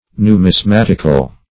Numismatic \Nu`mis*mat"ic\, Numismatical \Nu`mis*mat"ic*al\, a.